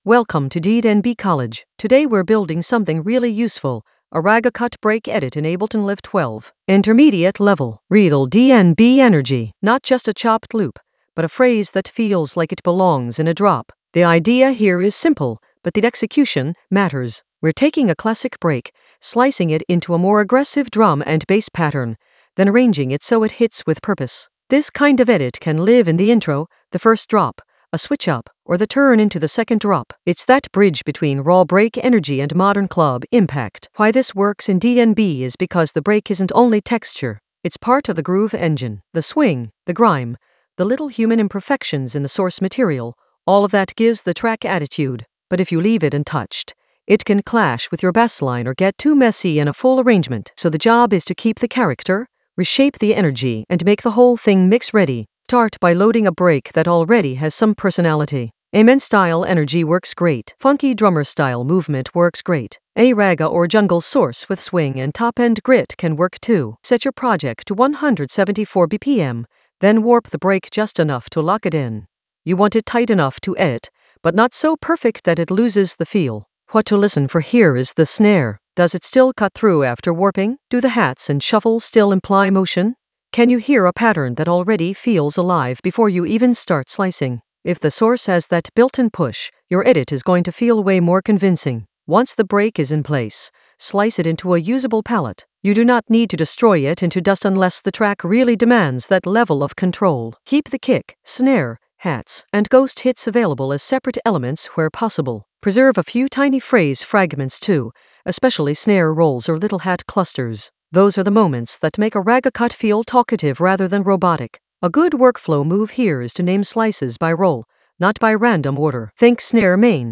An AI-generated intermediate Ableton lesson focused on Break Lab a ragga cut: design and arrange in Ableton Live 12 in the Edits area of drum and bass production.
Narrated lesson audio
The voice track includes the tutorial plus extra teacher commentary.